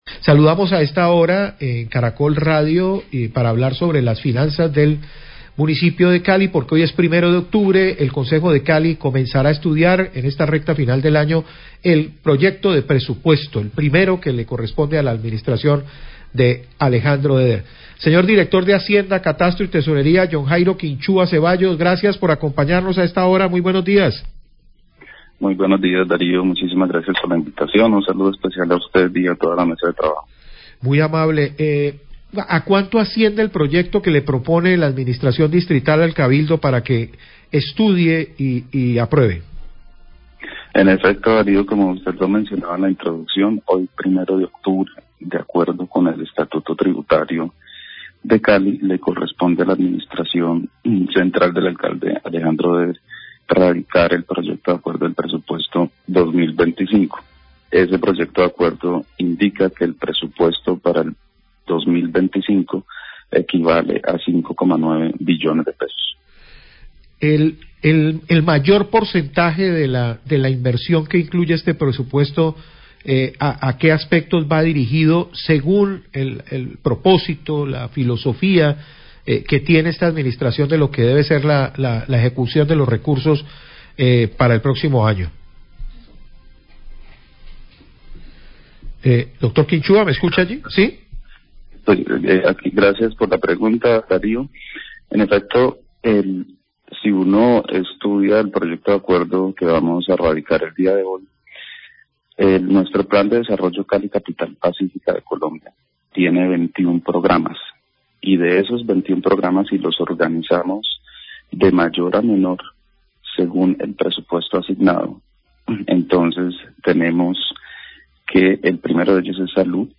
Radio
El Concejo de Cali evalúa presupuesto de alcaldía para 2025 y los proyectos efectuados con el mismo. Este presupuesto es el primero para la alcaldía Eder. el Director de hacienda, catastro y tesorería, Jhon Jairo Quinchua, responde entrevista al respecto.